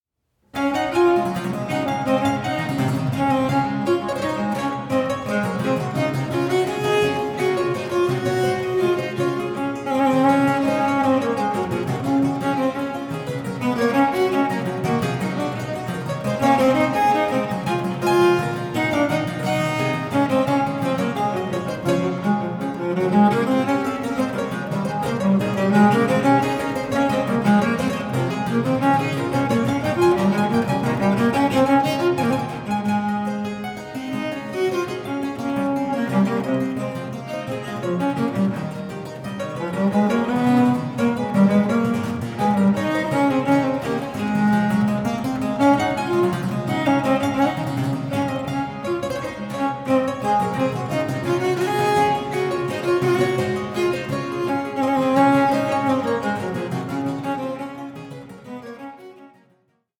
five-string cello piccolo
clavichord